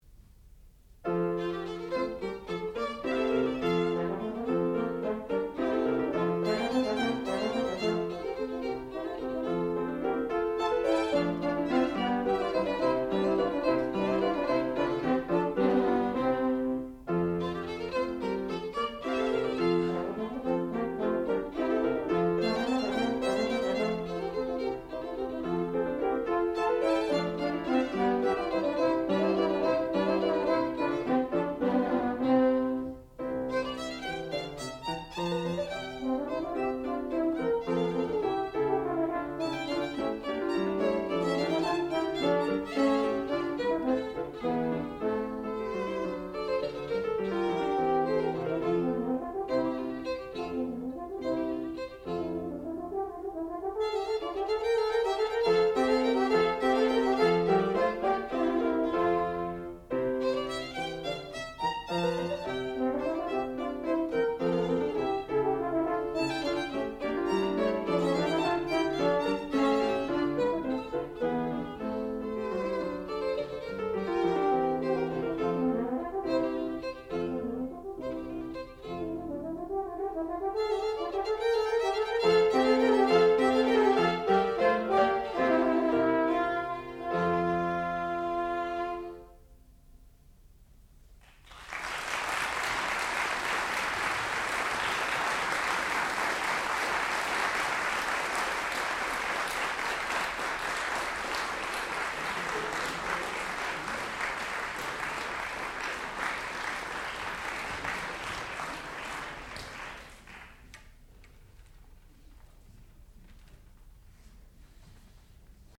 sound recording-musical
classical music
violin
piano
horn